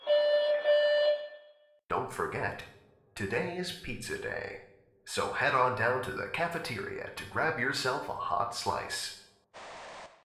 announcement1.ogg